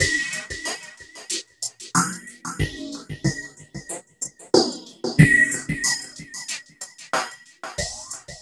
CHUG SWEEP-L.wav